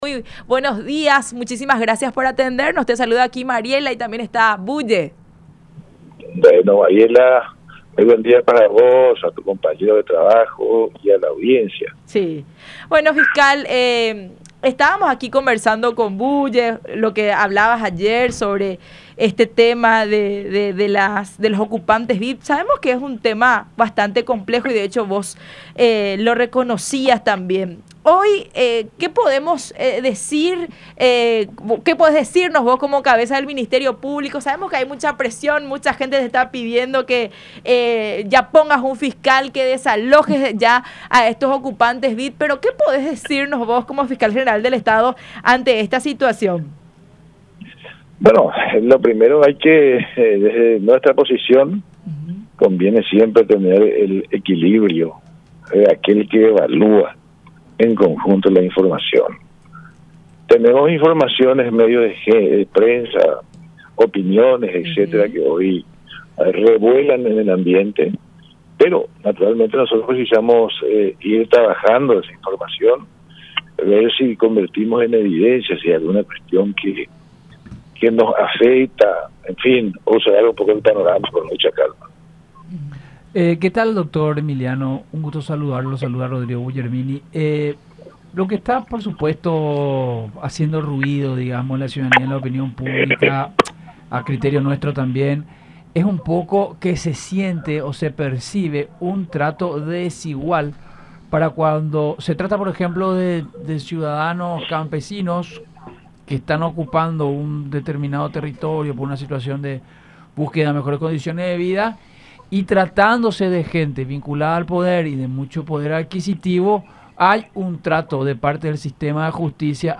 “No creo que se deba llevar el debate a ese extremo. No debemos dar connotación de que se trate de una cuestión de clases sociales o intereses económicos. La realidad es que el apoderamiento de un inmueble ajeno, tiene dos mecanismos de atención, la vía inmediata y el poder discrecional”, agregó en el programa “La Unión Hace la Fuerza por radio La Unión y Unión Tv.